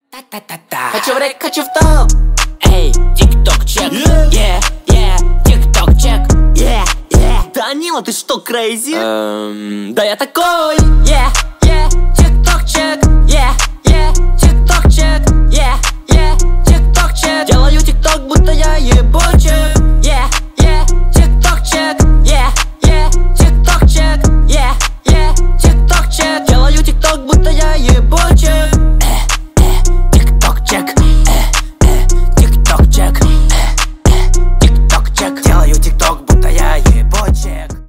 весёлые # кавер